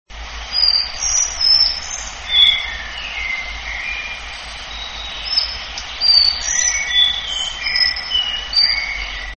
Muchołówka białoszyja - Ficedula albicollis